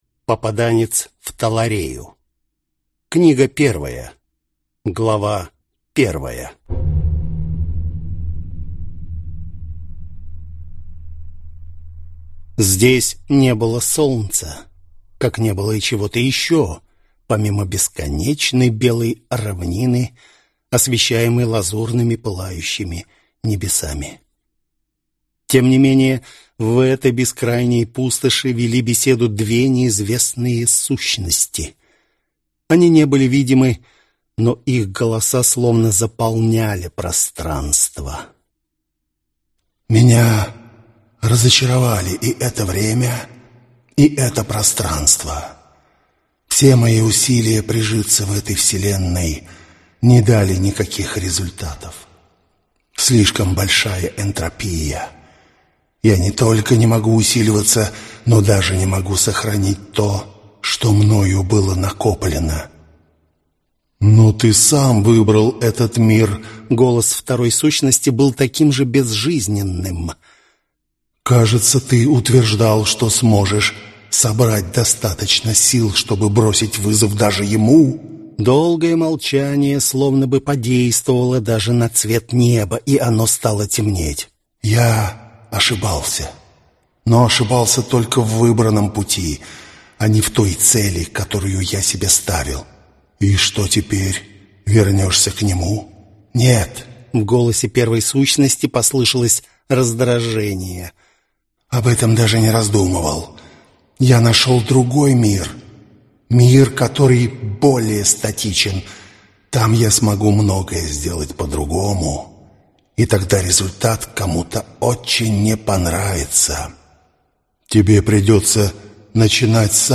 Аудиокнига Попаданец в Таларею | Библиотека аудиокниг